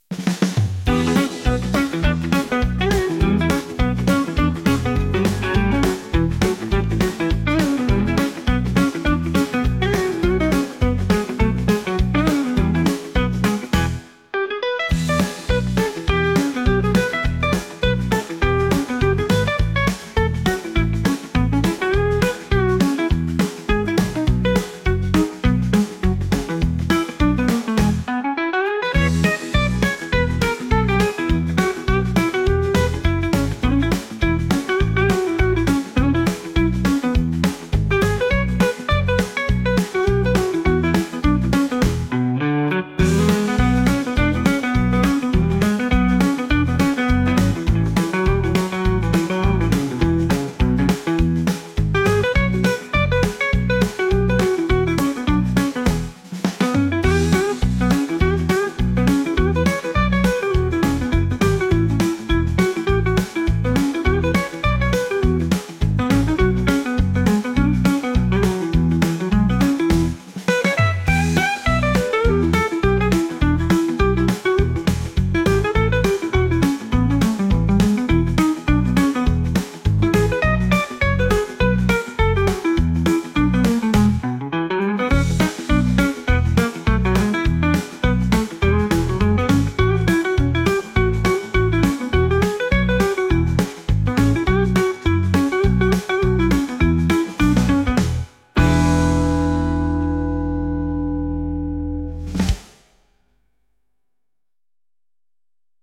コミカル